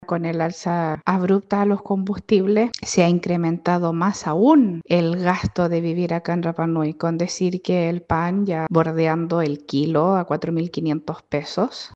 En tanto, desde Rapa Nui, la concejala Javiera Tepano advirtió que el alza del combustible ya se está reflejando en la vida diaria, con aumentos en el pan que “bordea el kilo a $4.000”, el transporte y la proyección de futuras alzas en servicios básicos.